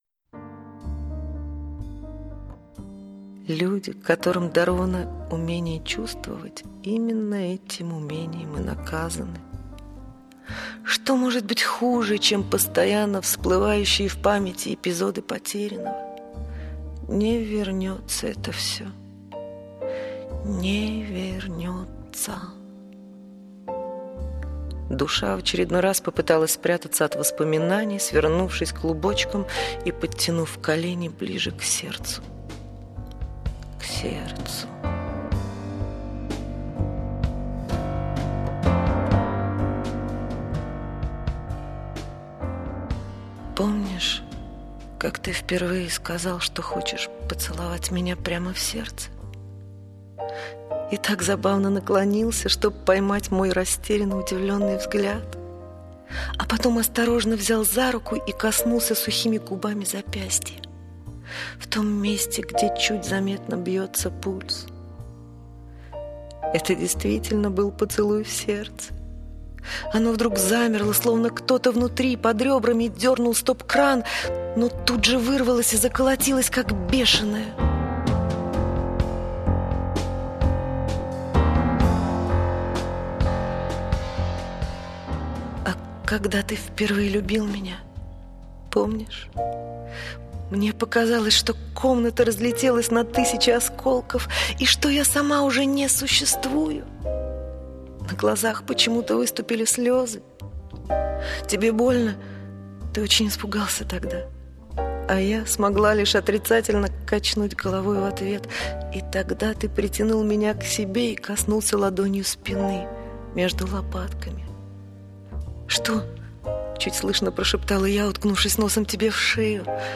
Ну и красивейшая мелодекламация